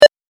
文字送りa単.mp3